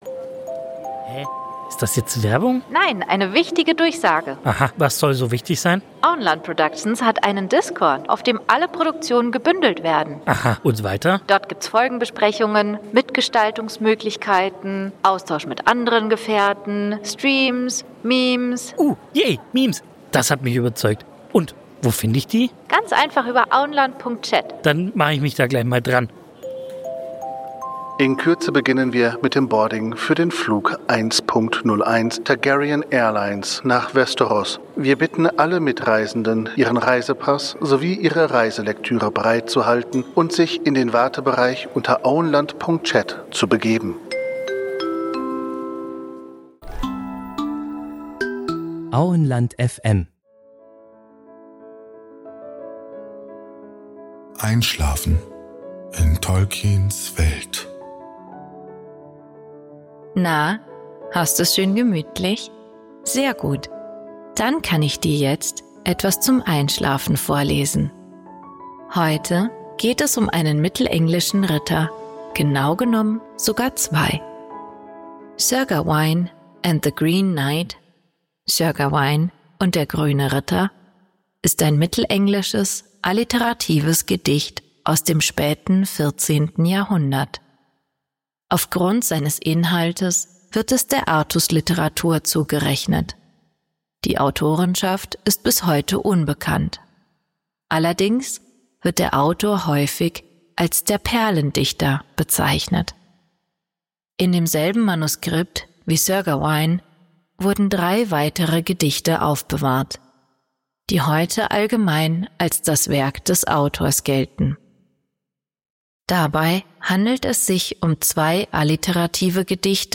Heute erzählen wir Dir zum Einschlafen etwas aus Tolkiens Welt. Dazu lesen wir Dir ausgewählte Artikel aus der Ardapedia vor.